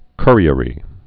(kûrē-ə-rē, kŭr-)